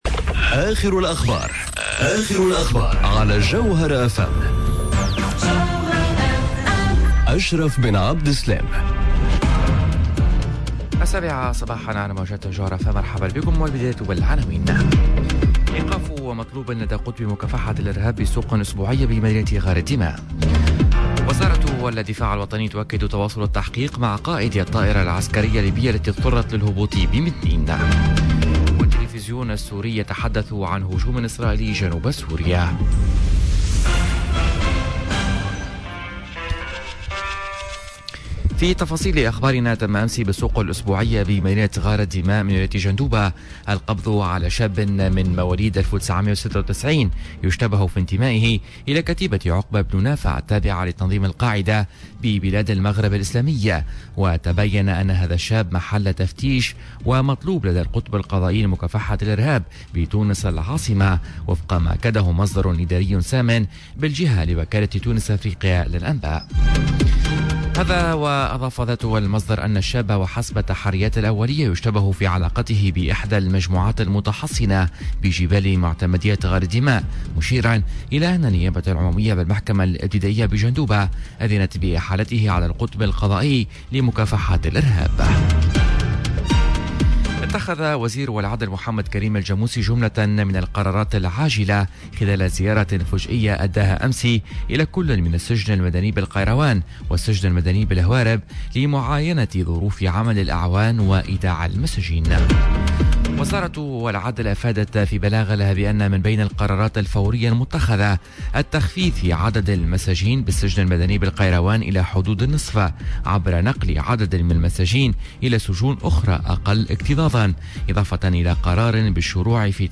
نشرة أخبار السابعة صباحا ليوم الإربعاء 24 جويلية 2019